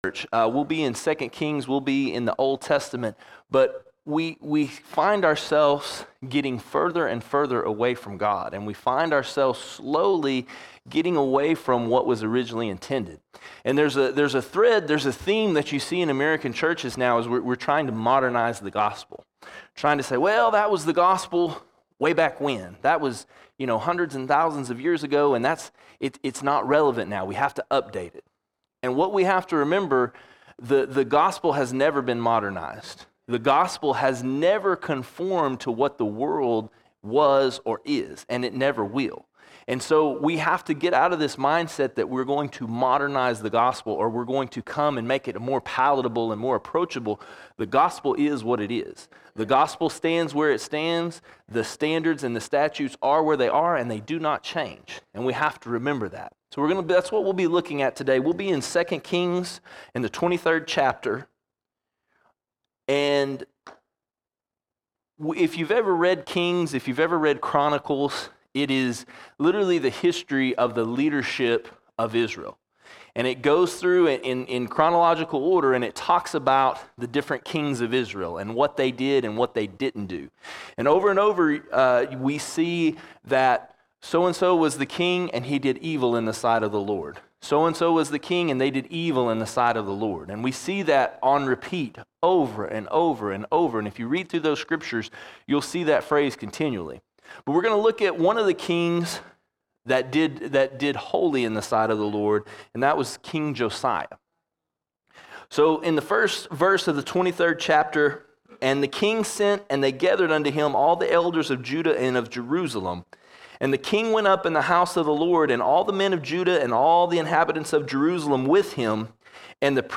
26 February 2024 Series: Sunday Sermons Topic: world All Sermons Spiritual Reform Spiritual Reform The churches of today are allowing the world to come in.